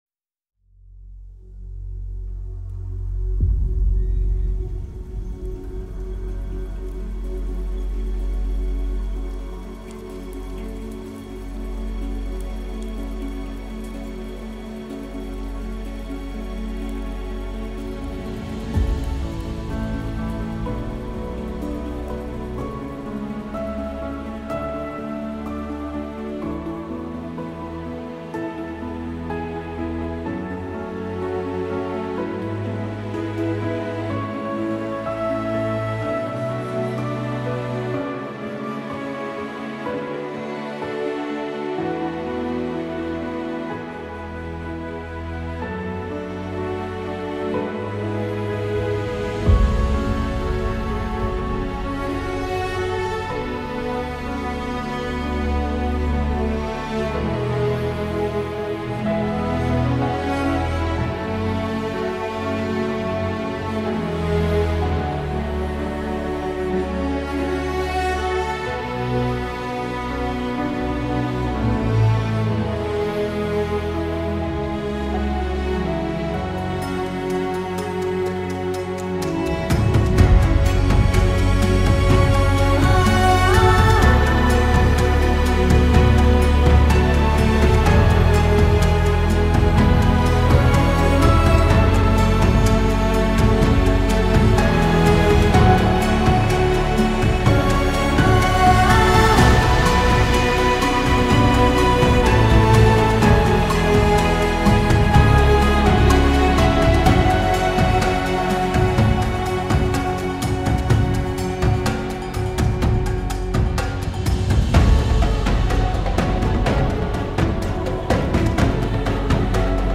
Instrumental.